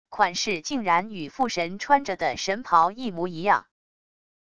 款式竟然与父神穿着的神袍一模一样wav音频生成系统WAV Audio Player